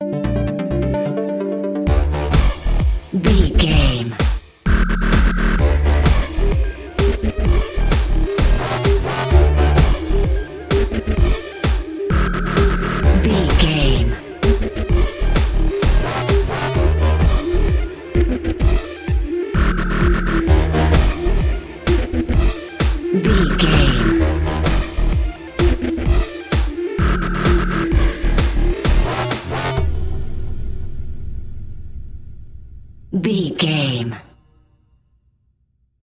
Aeolian/Minor
Fast
aggressive
dark
groovy
futuristic
drum machine
synthesiser
breakbeat
energetic
synth leads
synth bass